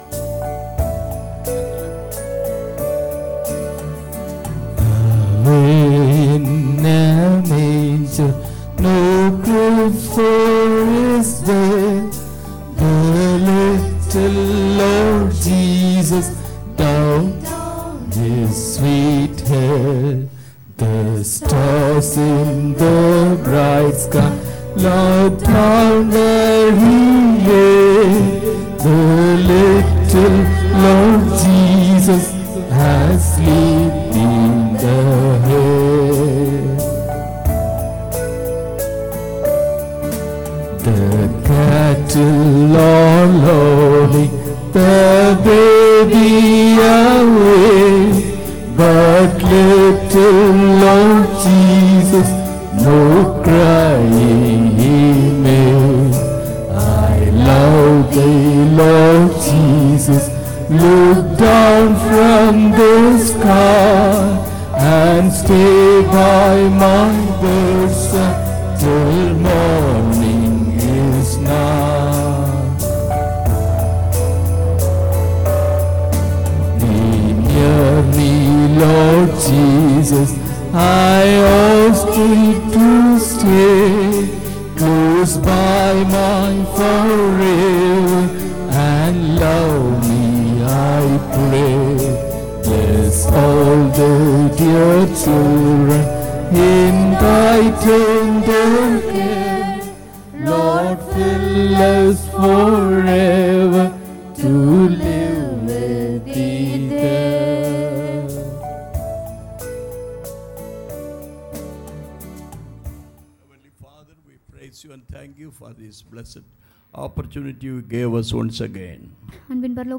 Passage: Matthew 11:28 Service Type: Sunday Morning Service